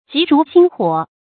注音：ㄐㄧˊ ㄖㄨˊ ㄒㄧㄥ ㄏㄨㄛˇ
讀音讀法：
急如星火的讀法